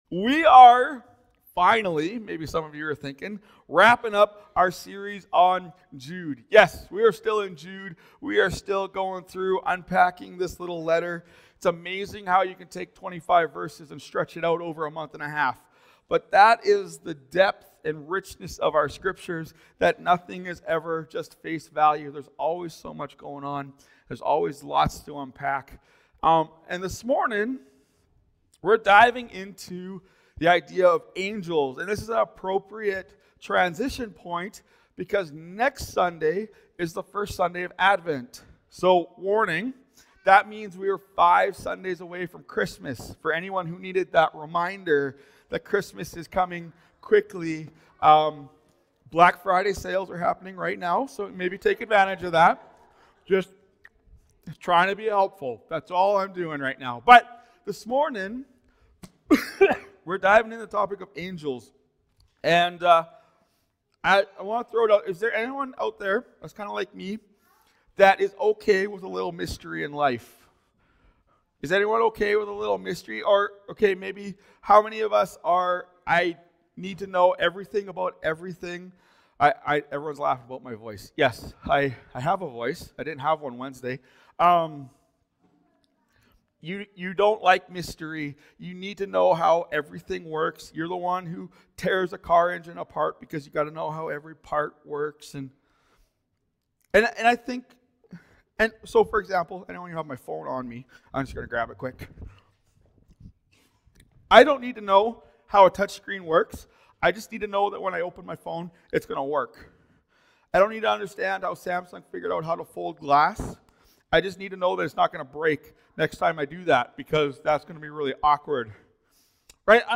Sermons | OneChurch